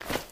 STEPS Dirt, Run 13.wav